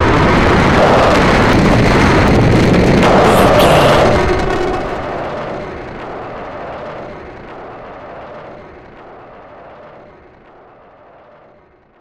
In-crescendo
Atonal
tension
ominous
dark
haunting
eerie
synth
keyboards
pads
eletronic